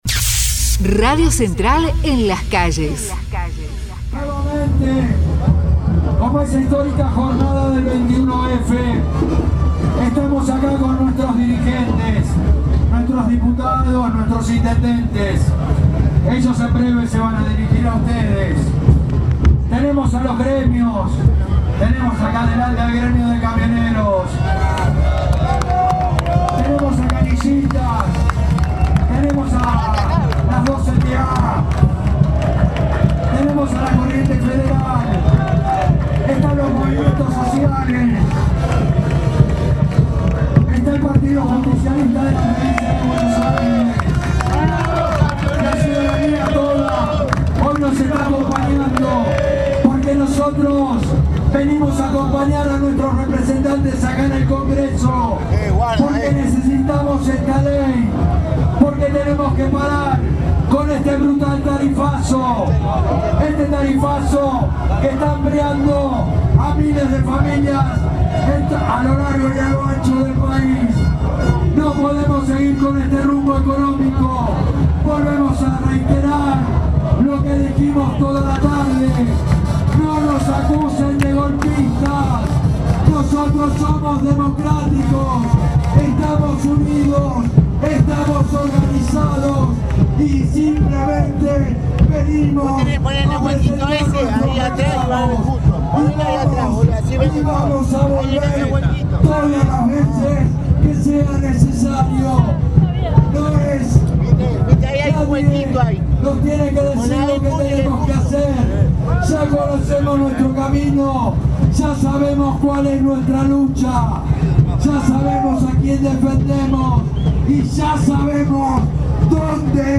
NO AL TARIFAZO / Acto frente al Congreso
Pablo Moyano, secretario general adjunto de la Federación Nacional de Trabajadores Camioneros; Hugo Yasky, diputado nacional por la provincia de Buenos Aires y secretario general de la CTA de los Trabajadores y Gustavo Menéndez, presidente del PJ Buenos Aires.